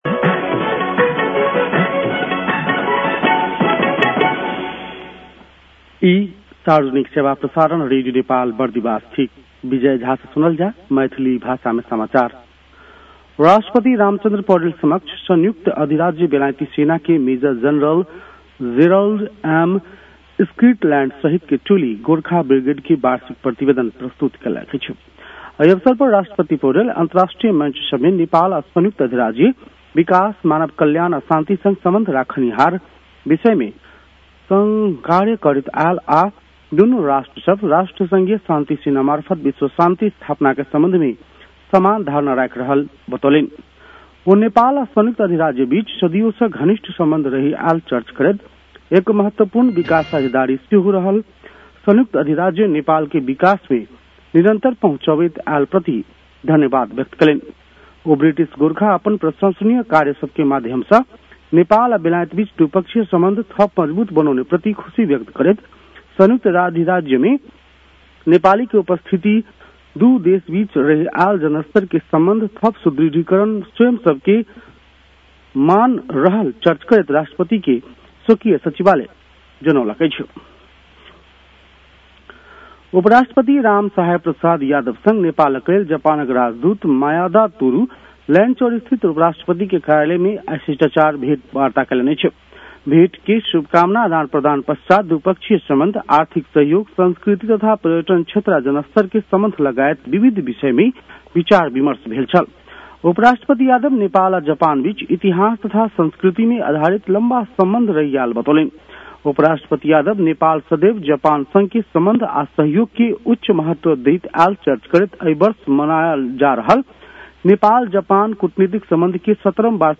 मैथिली भाषामा समाचार : २६ माघ , २०८२